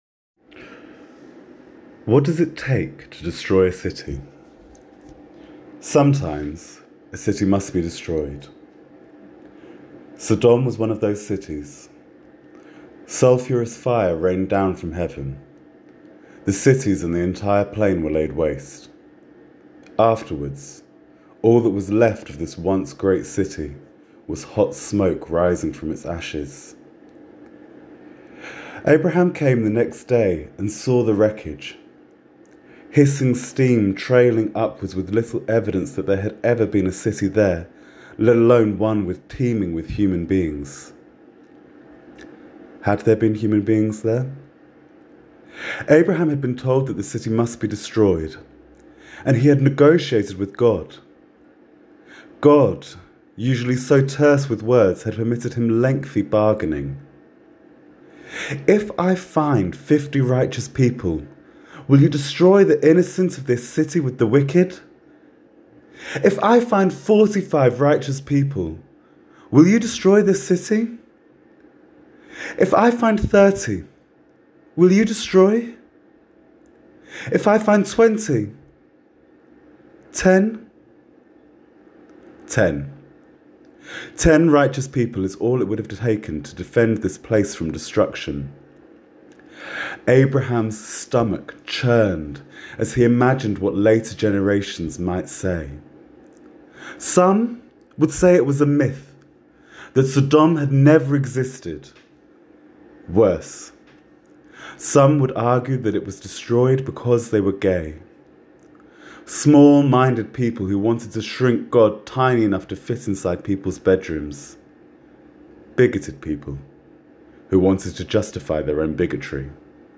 I wrote this sermon for the Leo Baeck College newsletter.